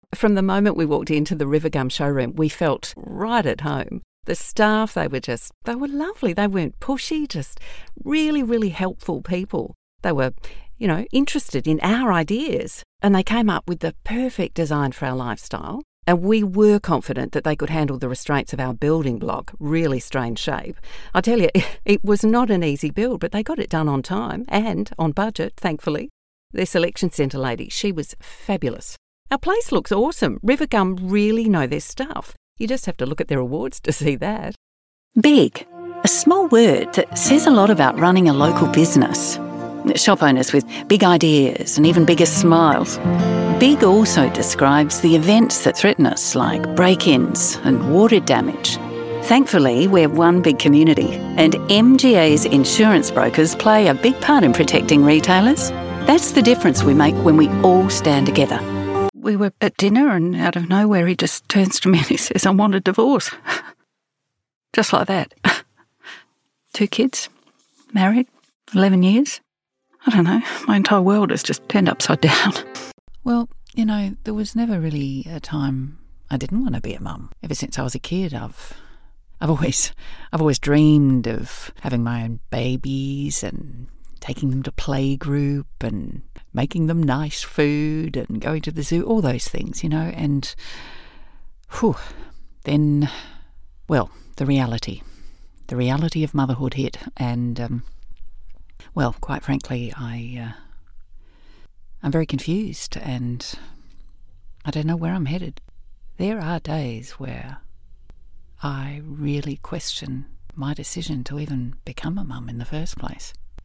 Female
English (Australian)
Adult (30-50), Older Sound (50+)
Natural Speak
Everyday Conversational Style
Words that describe my voice are sensual, conversational, authoritative.
All our voice actors have professional broadcast quality recording studios.
02181._Natural_conversational_style.mp3